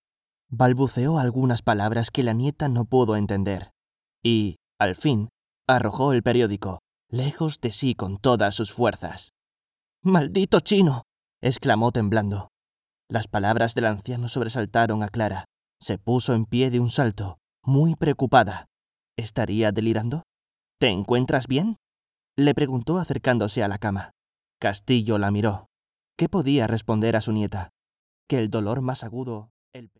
Spanish speaker, voice over, young voice, voice actor, station voice.
kastilisch
Sprechprobe: eLearning (Muttersprache):